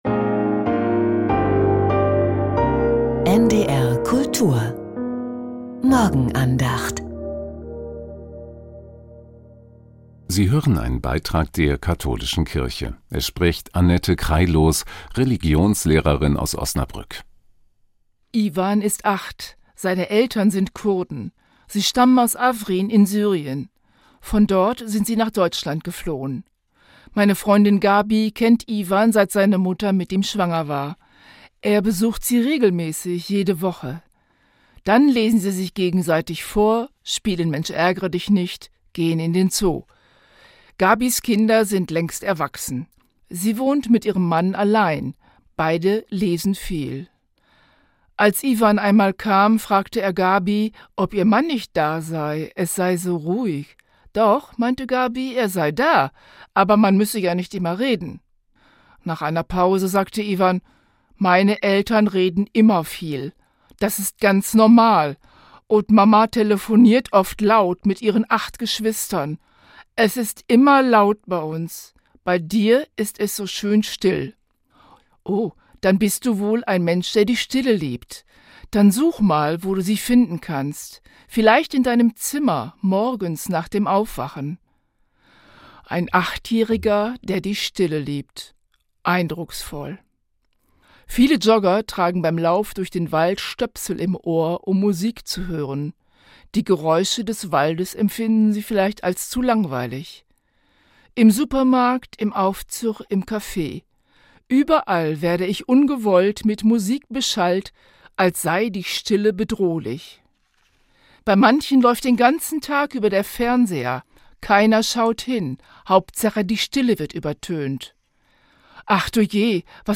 Stille als Raum für die Seele: Die Morgenandacht